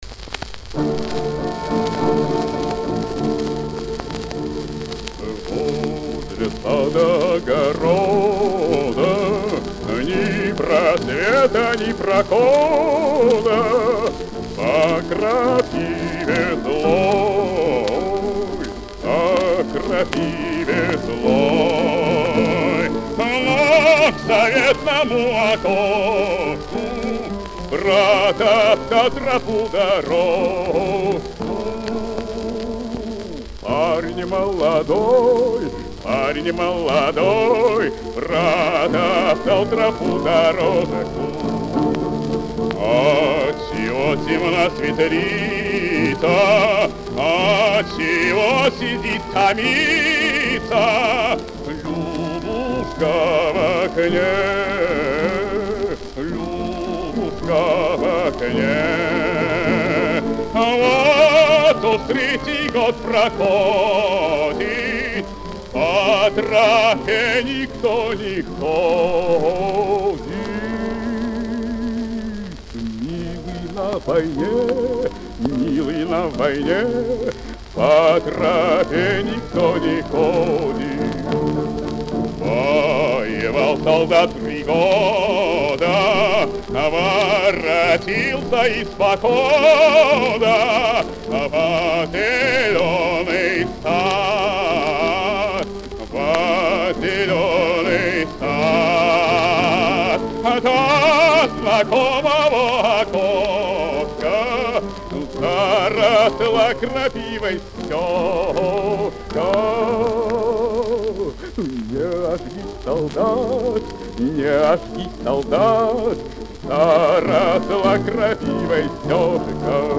баритон
секстет домр п